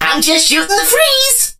lou_start_vo_04.ogg